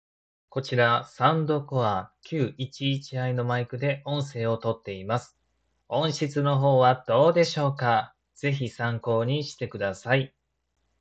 Q11i」マイクの音質を確認します。
むしろクリアに感じるほどでした (/ω＼)